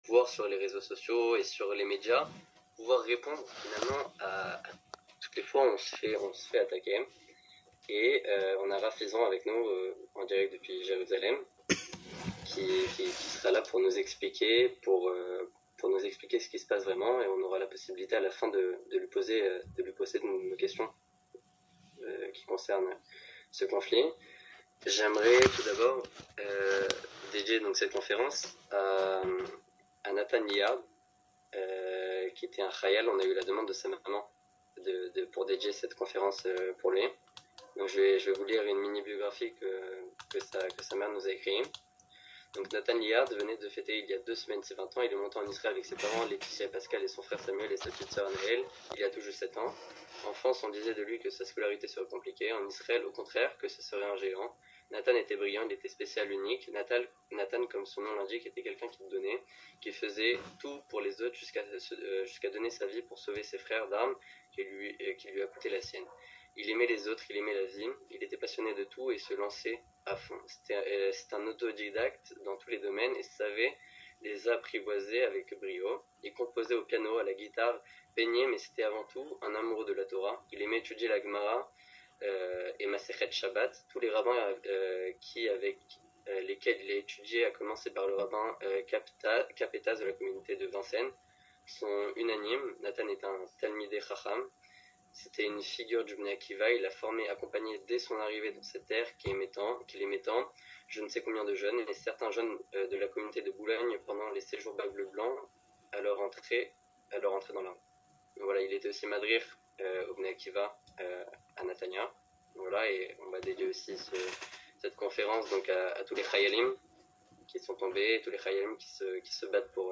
Questions / Reponses avec le Bne Akiva
Questions-Reponses-avec-le-Bne-Akiva.mp3